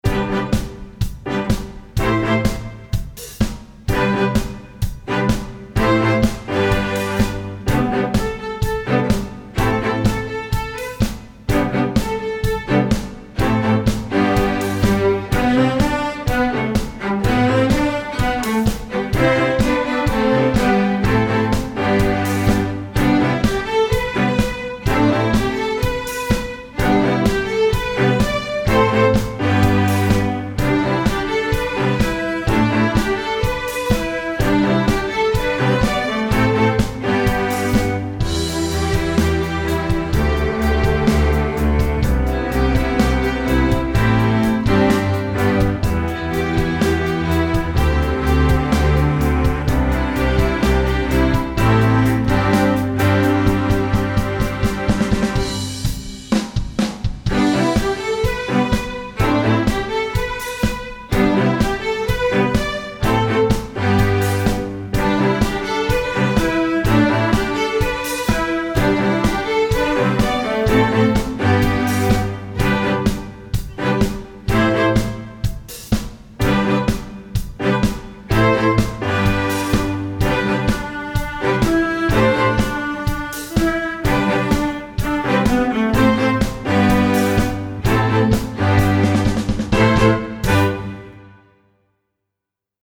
pop
Piano accompaniment part:
Drums part: